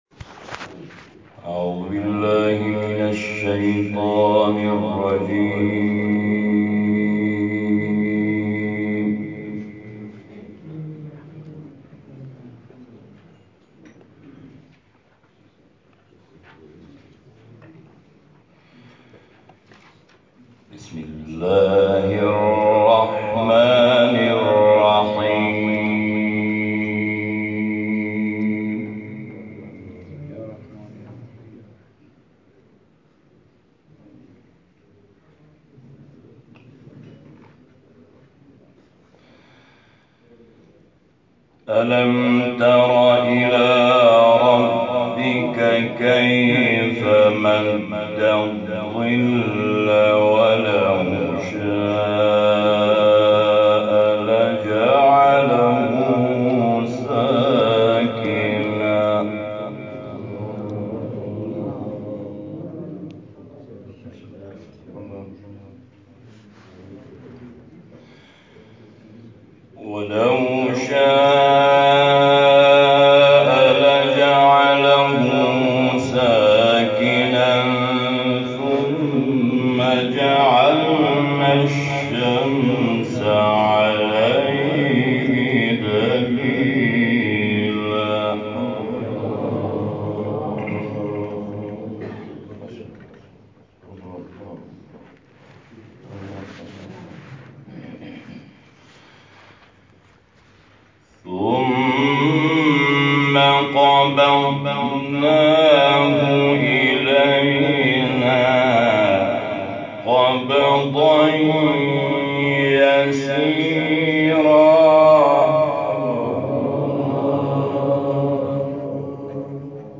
تلاوت و فرازی شنیدنی چندین آیه مبارکه از سوره فرقان